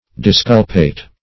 Disculpate \Dis*cul"pate\, v. t. [imp.